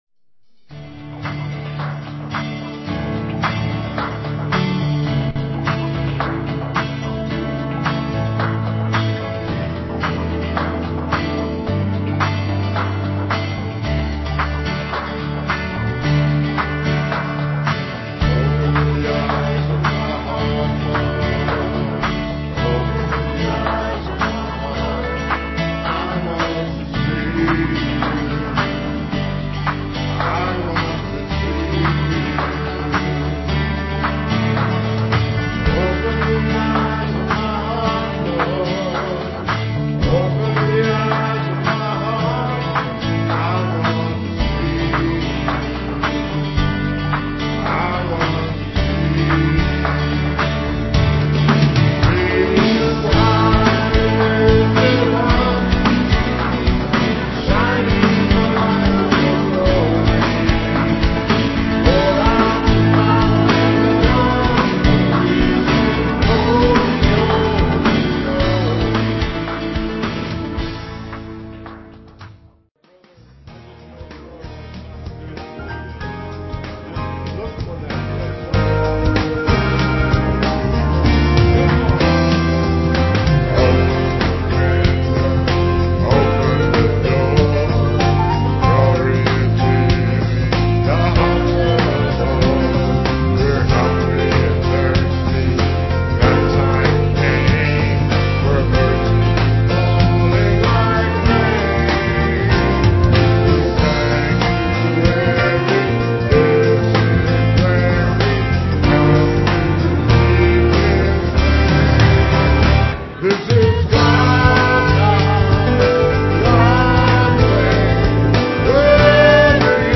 piano
digital piano.